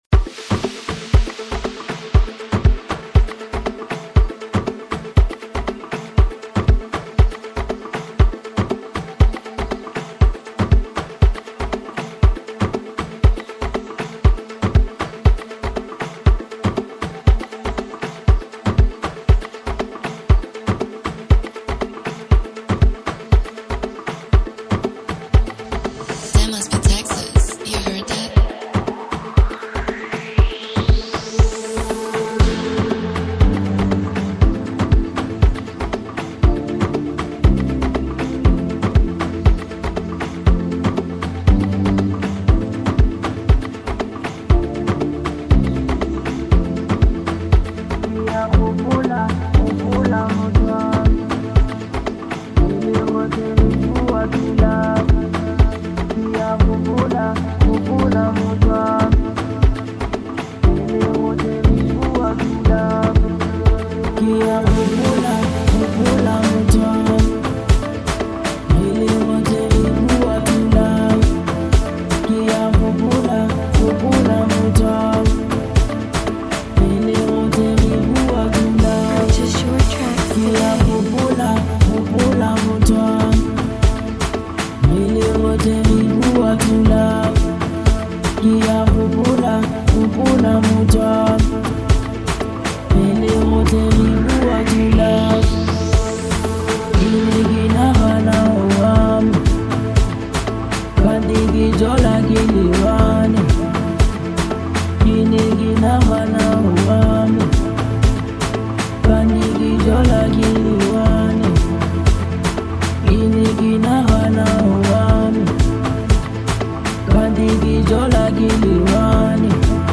Genre : House